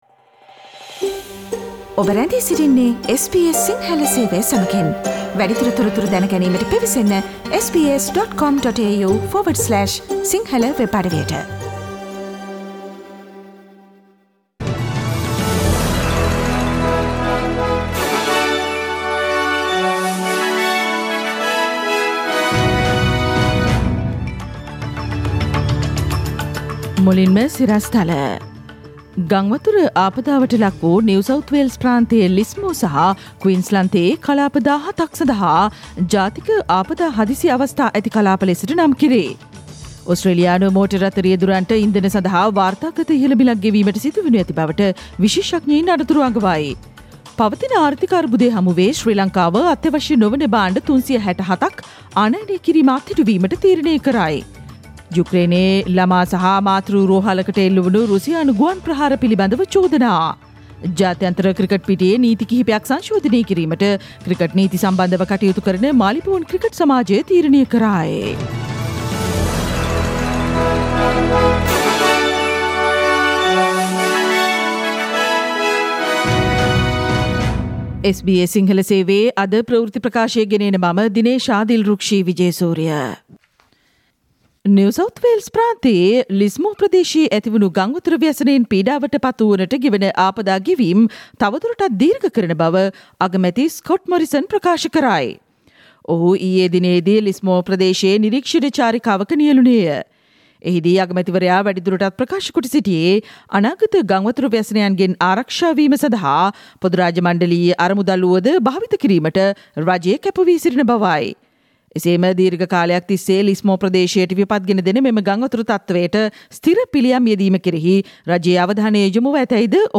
ඉහත චායාරූපය මත ඇති speaker සලකුණ මත click කොට මාර්තු මස 10 වන බ්‍රහස්පතින්දා SBS සිංහල ගුවන්විදුලි වැඩසටහනේ ප්‍රවෘත්ති ප්‍රකාශයට ඔබට සවන්දිය හැකියි